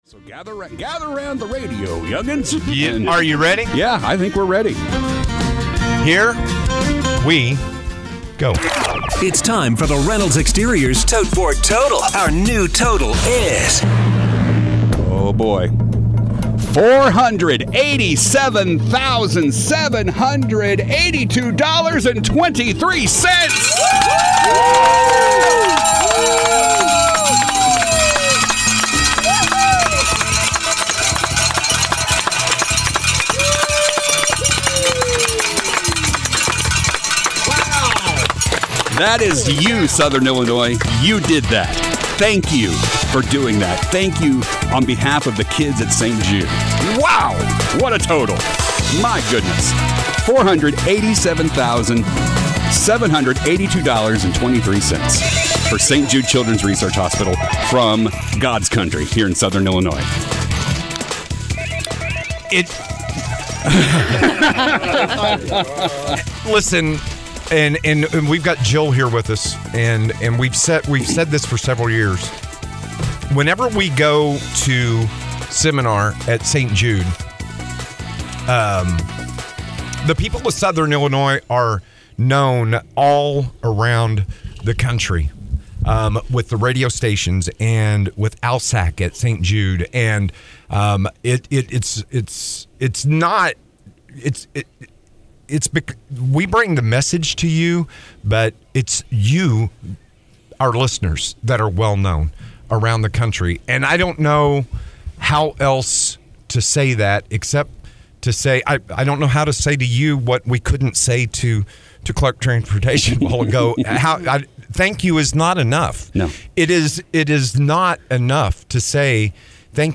This was an amazing year for the kids, and in case you missed it, here are the final moments from our 2026 St. Jude Radiothon!